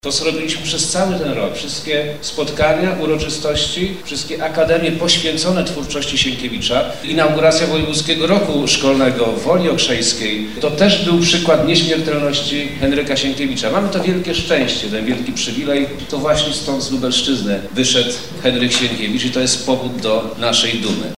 Na scenie amfiteatru kościoła na Poczekajce zaprezentowano widowisko muzyczne na podstawie noweli Henryka Sienkiewicza: „Janko Muzykant”.